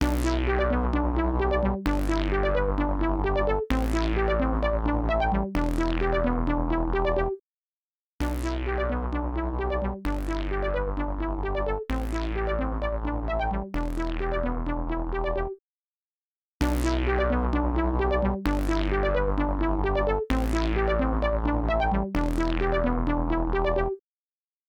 EChannel | Synth | Preset: Bass Limiter
EChannel-Bass-Limiter.mp3